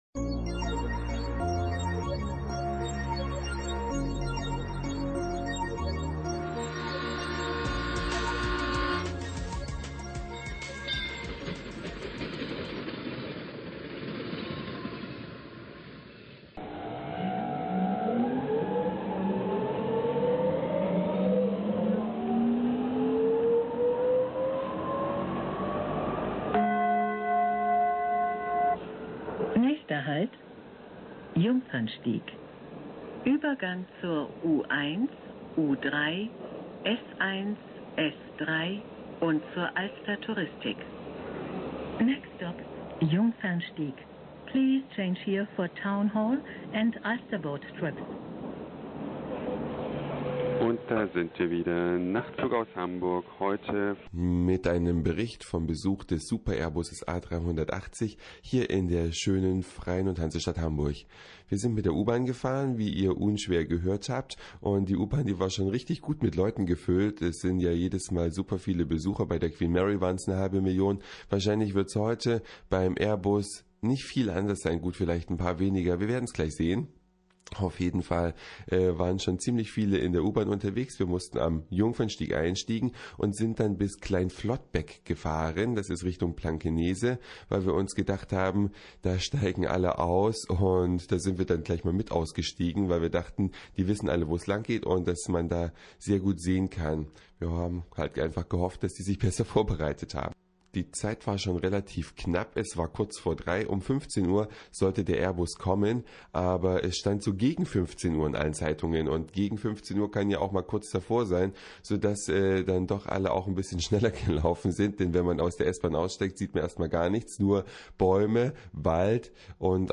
Eine Reise durch die Vielfalt aus Satire, Informationen, Soundseeing und Audioblog.
Queen Mary der Lüfte ist aber auch direkt über uns geflogen.